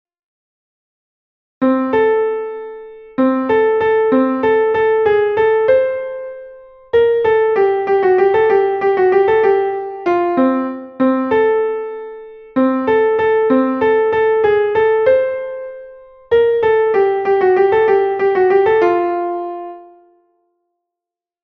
entoacionacapela1.mp3